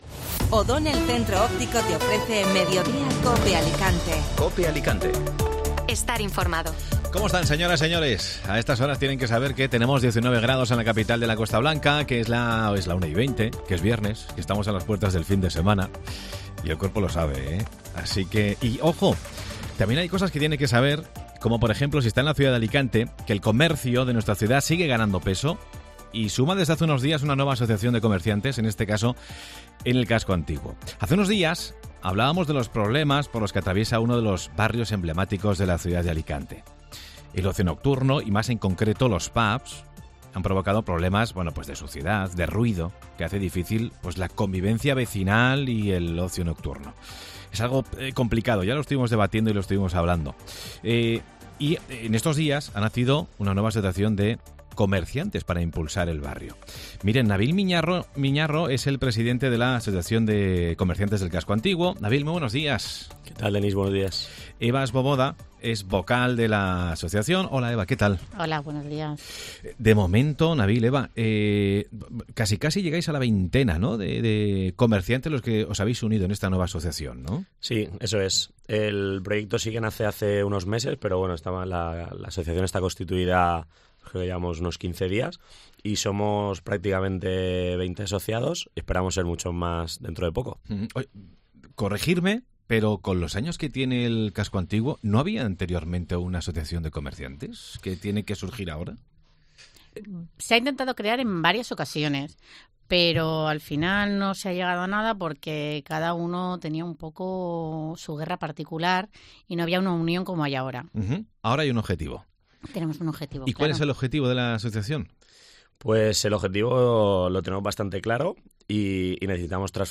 Escucha la entrevista completa en Mediodía COPE.